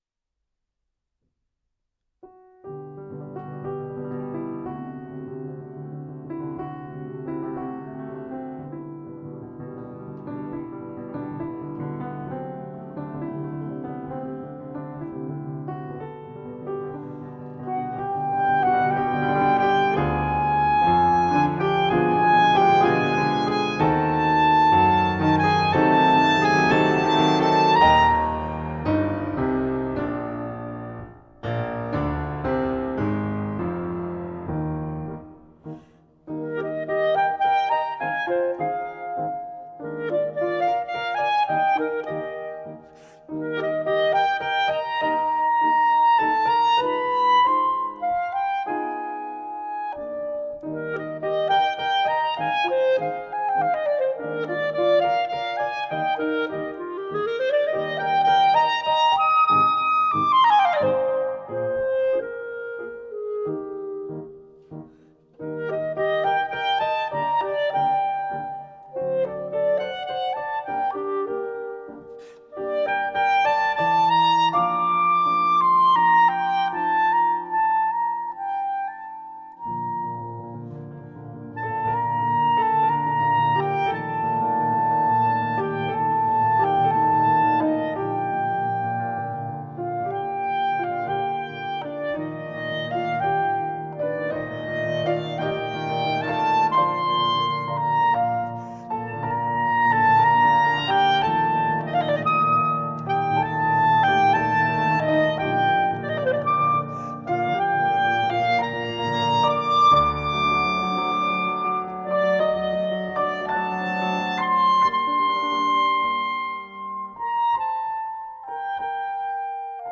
Il supporto su cui ho dipinto è uno spartito originale della seconda metà dell’Ottocento: “Ruy Blas – Fantasia a Capriccio per Pianoforte” di Filippo Fasanotti, basata sull’opera lirica omonima del Cav.
Ruy-Blas-di-F.-Marchetti-Fantasia.mp3